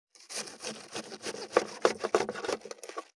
536厨房,台所,野菜切る,咀嚼音,ナイフ,調理音,まな板の上,料理,
効果音